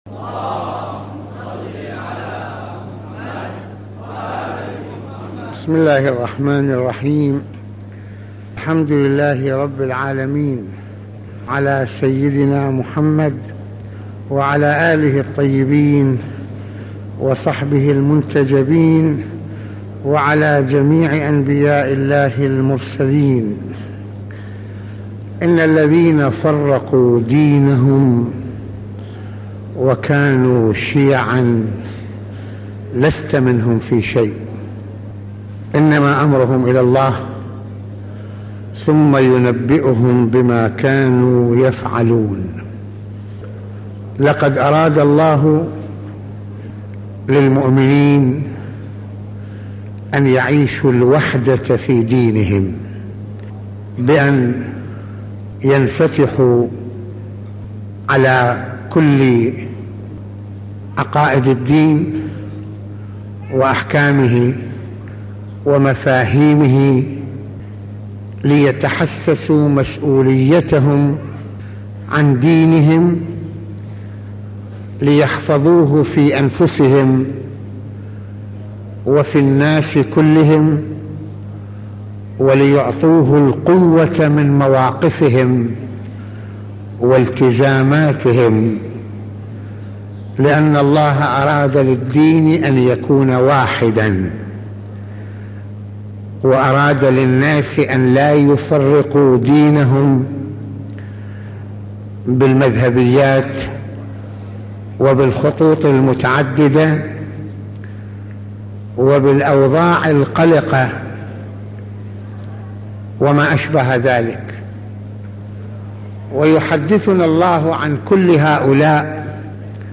موعظة ليلة الجمعة المكان : مسجد الإمامين الحسنين (ع)